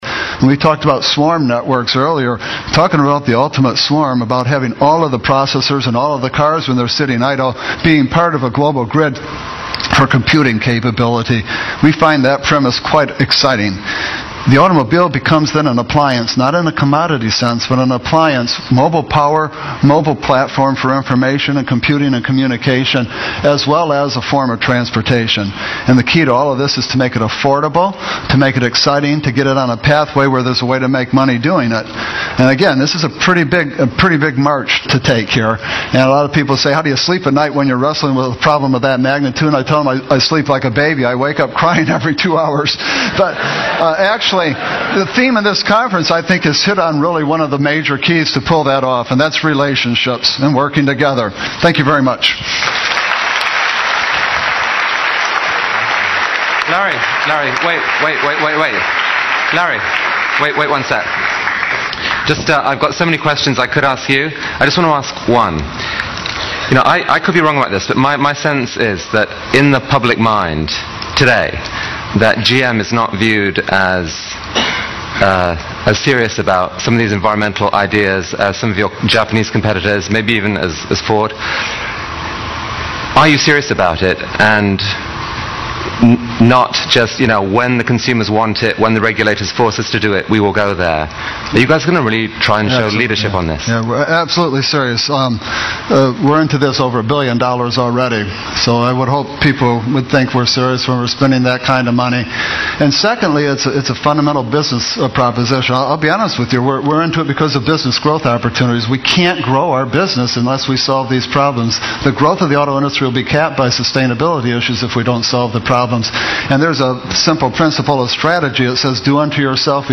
这些财富精英大多是世界著名公司的CEO，在经济领域成就斐然。在演讲中他们或讲述其奋斗历程，分享其成功的经验，教人执着于梦想和追求；或阐释他们对于公司及行业前景的独到见解，给人以启迪和思考。